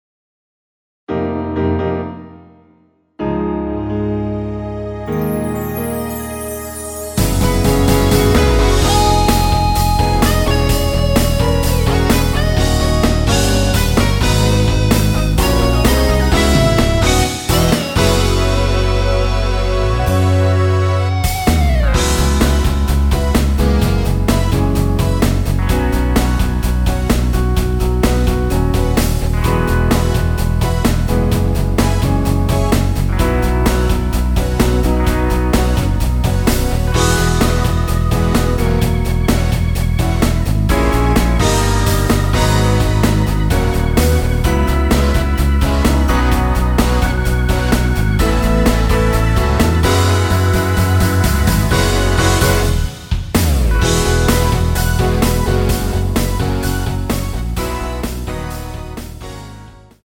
전주 없이 시작 하는곡이라 전주 만들어 놓았습니다.(미리듣기 참조)
앞부분30초, 뒷부분30초씩 편집해서 올려 드리고 있습니다.
중간에 음이 끈어지고 다시 나오는 이유는